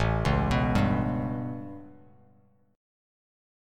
A9 Chord
Listen to A9 strummed